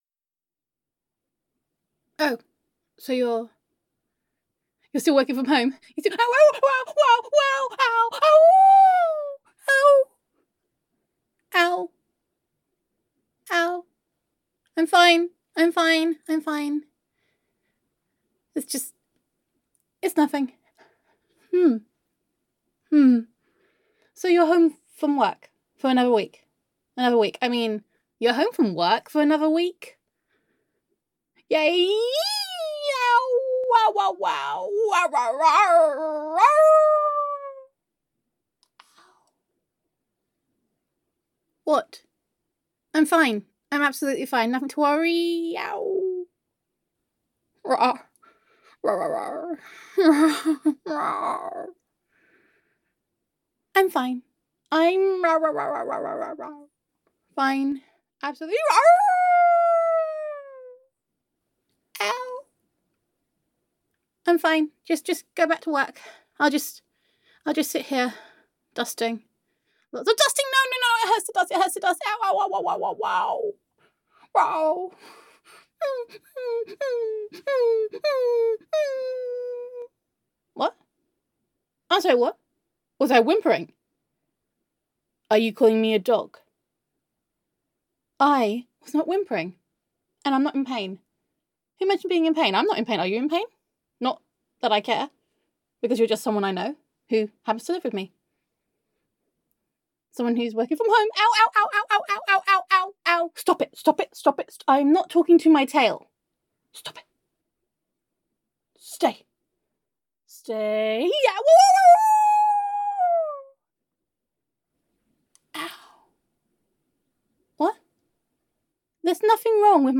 [F4A] Today Has Been Ruff [Ouchies][Tail Strain][Comfort][Asking for Pets][but Not Because I Like You][Snuggles][Wagging Tail of Mass Destruction][Gender Neutral][Your Tsundere Werewolf Roommate Is Definitely Not Happy That You Are Home]